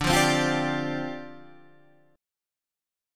D Minor 11th